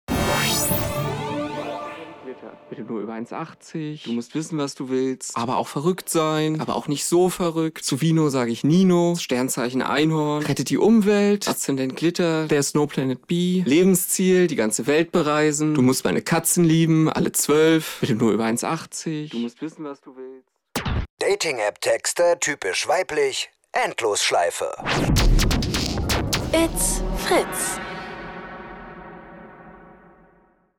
Endlosschleife "Dating-App-Texte" (typisch weiblich) | Fritz Sound Meme Jingle | JinglesApp